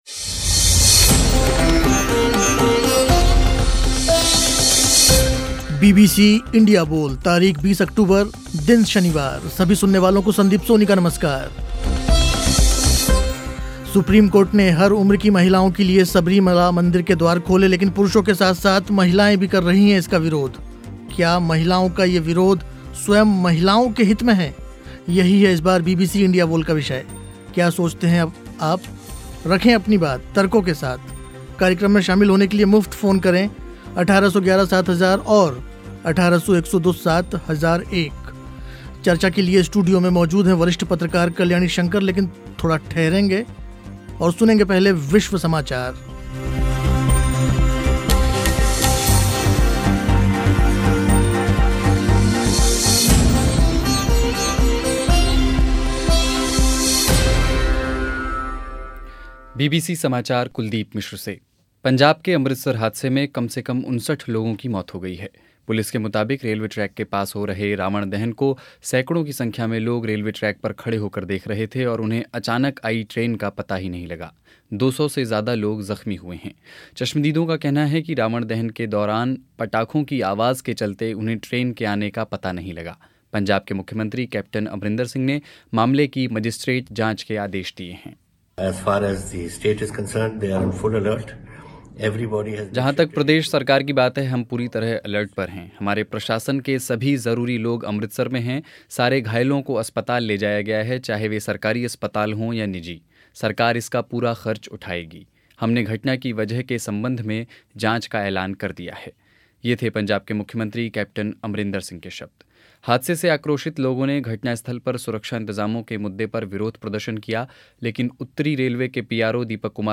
क्या महिलाओं का ये विरोध, स्वयं महिलाओं के हित में हैं? चर्चा के लिए स्टूडियो में मौजूद थीं